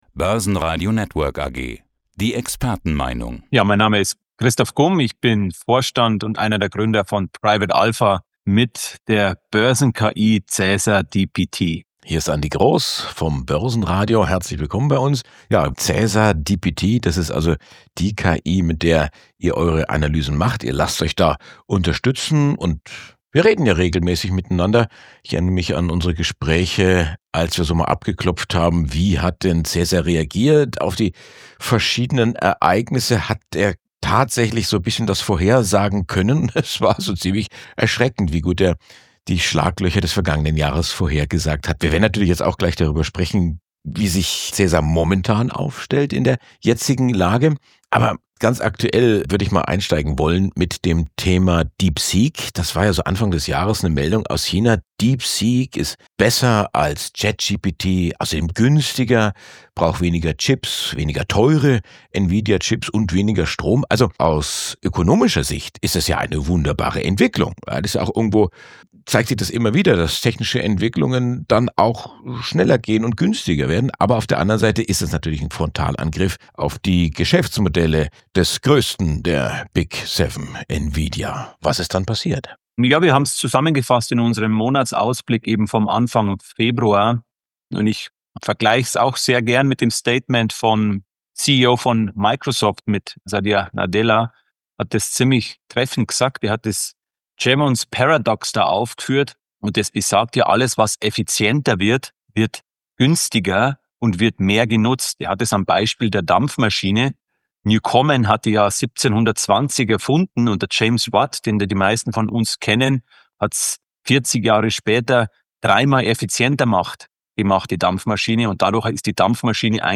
Hier geht’s zum Podcast Interview mit dem Börsenradio: